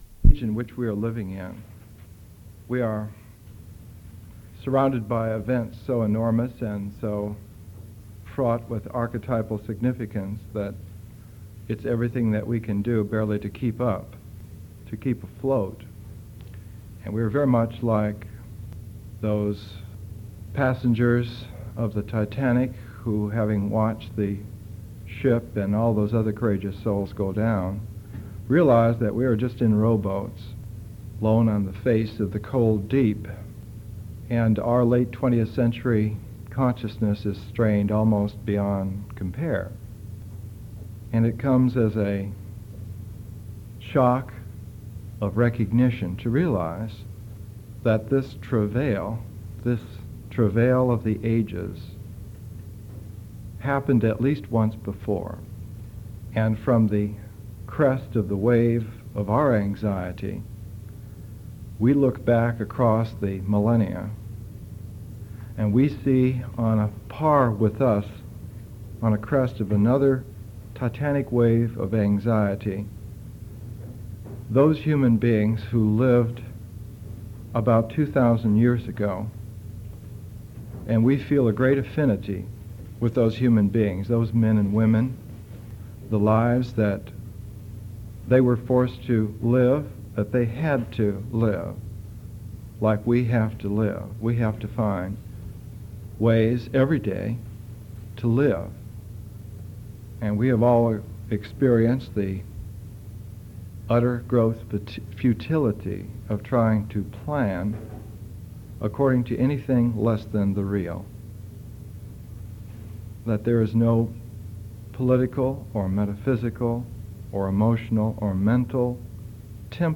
Presentations are 1-1.5 hours: an initial session of 30-45 minutes, an intermission for discussion or contemplation, and a second 30-45 minute session.